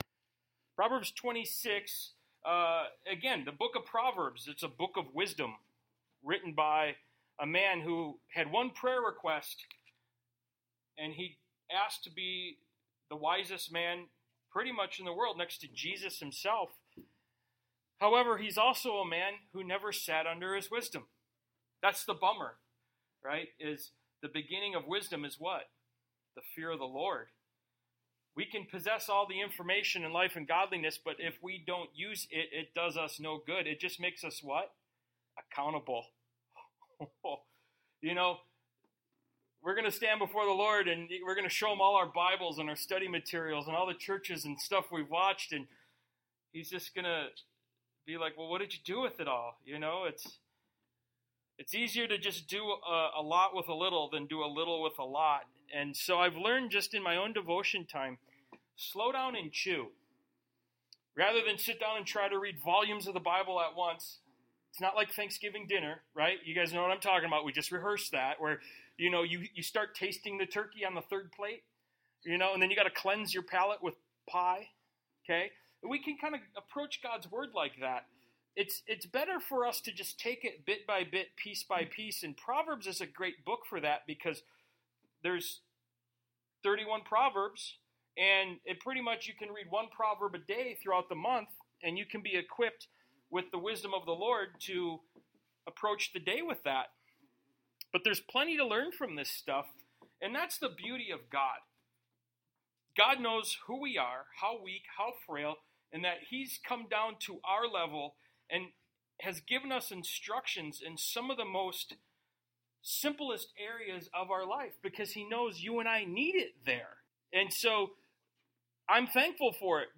Service Type: Sunday Morning Bible Text: So a curse without cause shall not alight.